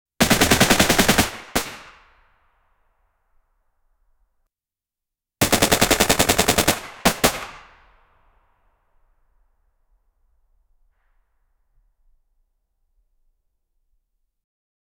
realistic bmp-2 2a-42 30mm auto cannon gunshot sounds (middle speed, hi-speed ranges) 0:15 Created Apr 13, 2025 10:53 AM realistic btr-80 2a-42 30mm cannon gunshot sounds (middle speed, hi-speed ranges) 0:15 Created Apr 13, 2025 10:52 AM